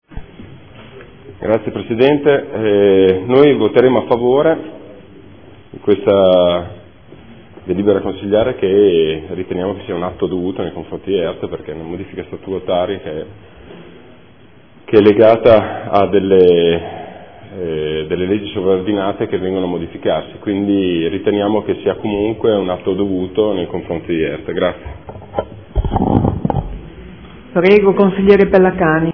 Seduta del 15 gennaio 2015. Proposta di deliberazione: Fondazione Emilia Romagna Teatro – Approvazione proposte di modifica statutarie. Dichiarazioni di voto